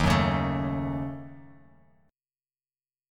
Ebm7b5 chord